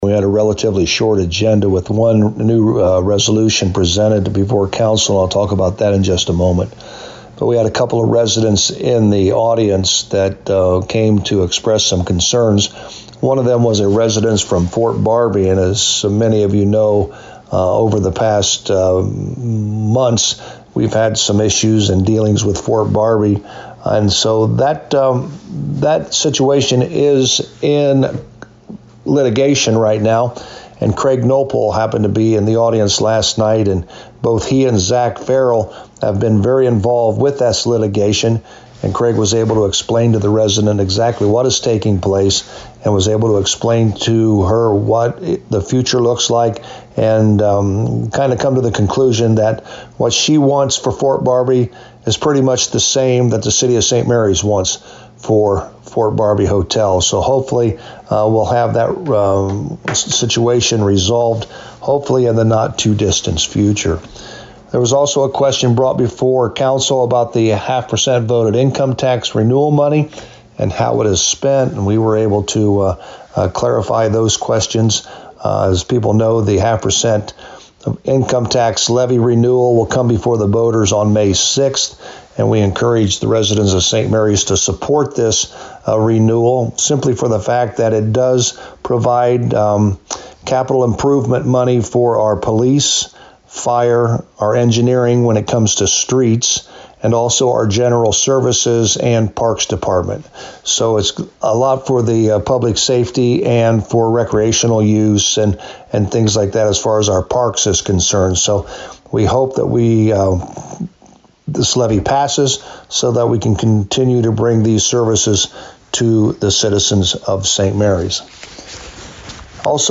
To hear the summary with St Marys Mayor Joe Hurlburt: